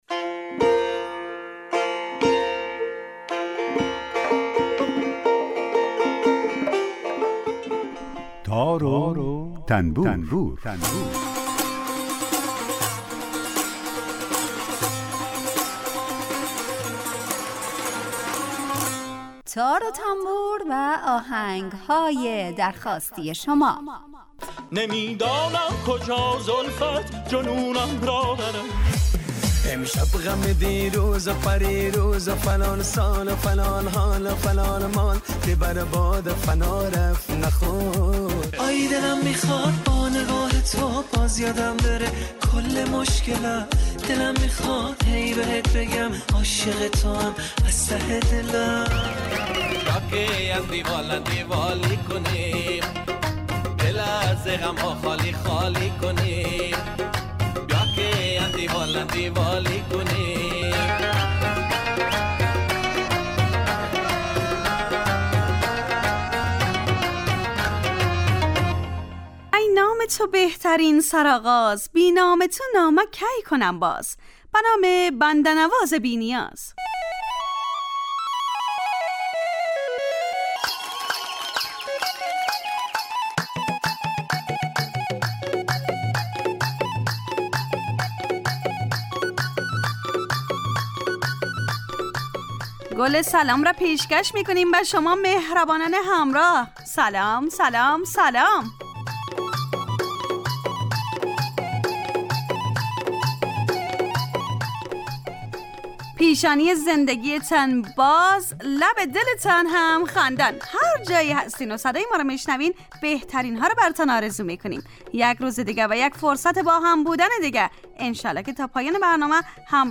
برنامه تار و تنبور به جای گلچین موسیقی ساخته میشه 40 دقیقه هر روز برنامه ی آهنگ های درخواستی هر روز از رادیو دری به مدت 40 دقیقه برنامه ای با آهنگ های درخو...